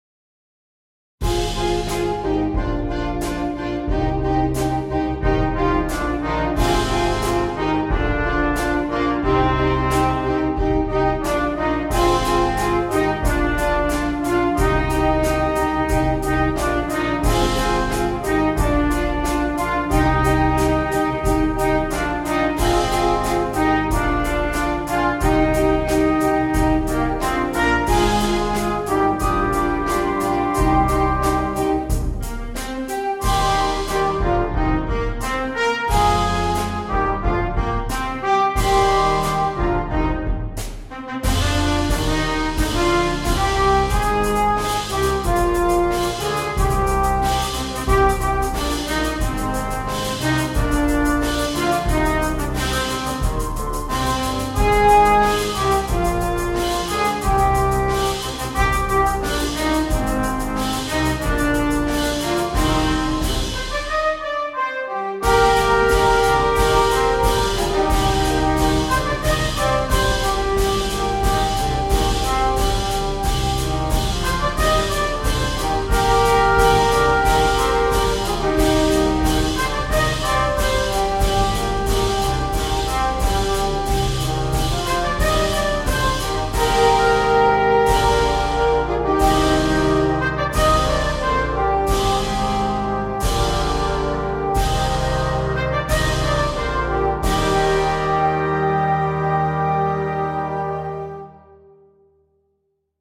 Virtual recordings were made using NotePerfomer 3.